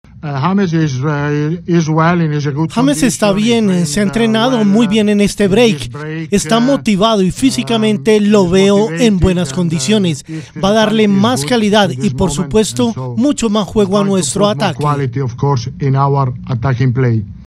(Carlo Ancelotti, DT del Everton)
Por su puesto va a darle más calidad a nuestro juego de ataque”, señaló el entrenador en conferencia de prensa.